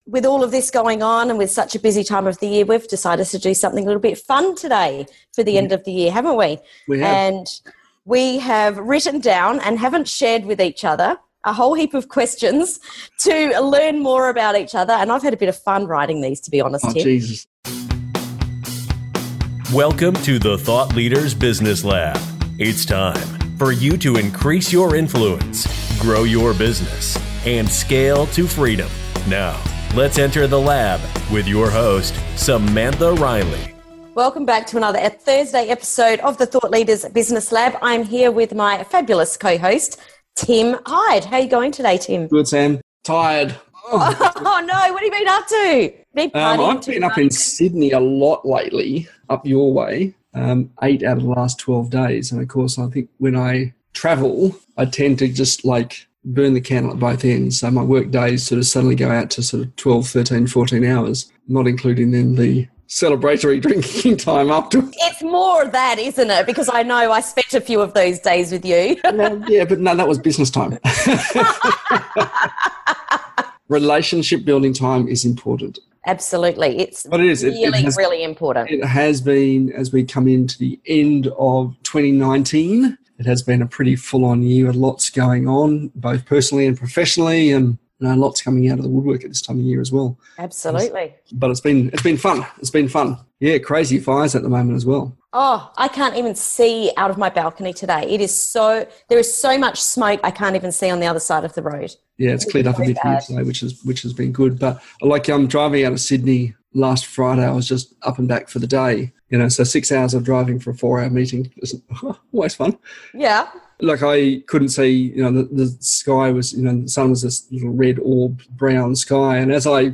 Join us as we come out with guns blazing and shoot each other with some of the most fascinating questions we could come up with. And as always, we share a lot of laughs.